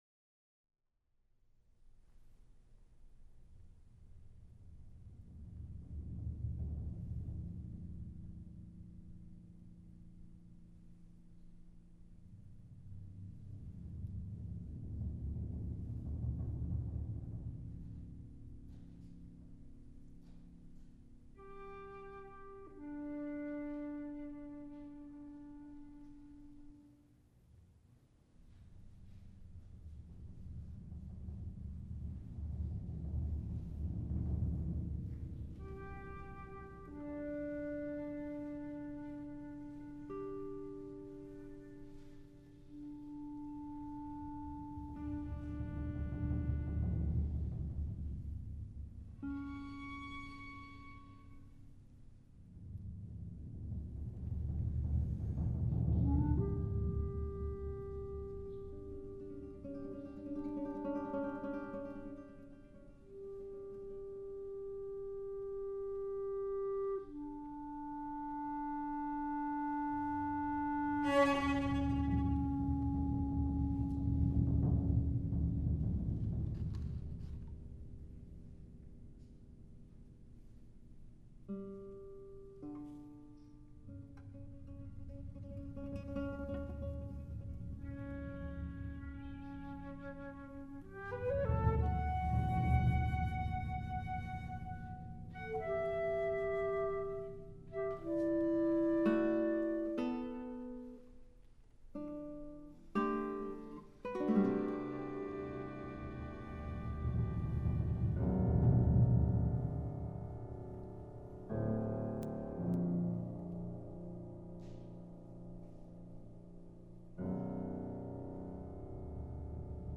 guitar soloist